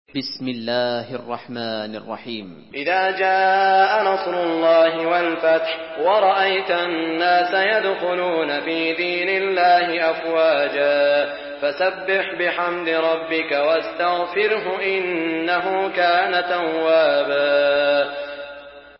Surah আন-নাসর MP3 in the Voice of Saud Al Shuraim in Hafs Narration
Murattal Hafs An Asim